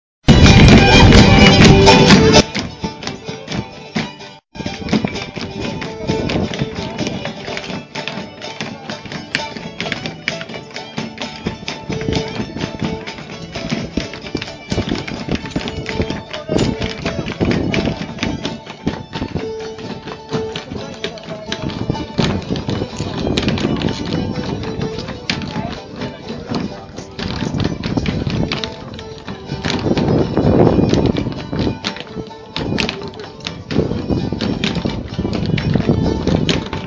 Appalachian Dance recorded closer